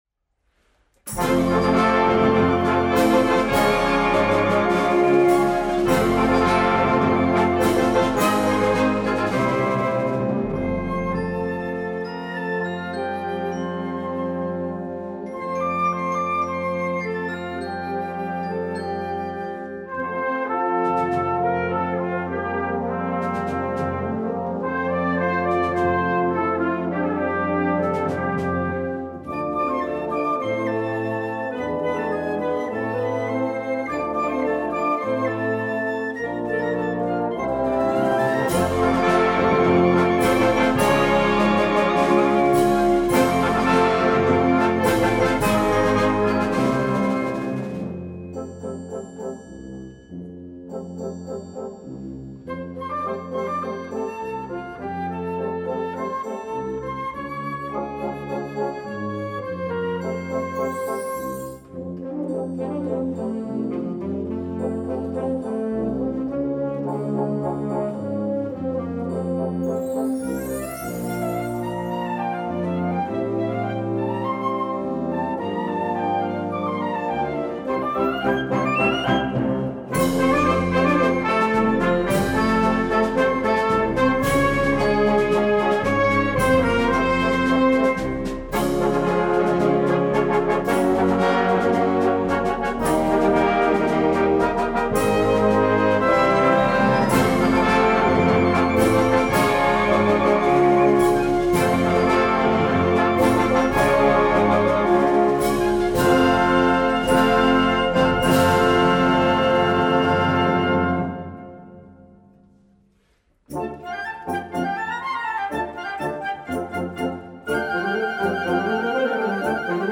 Brano da concerto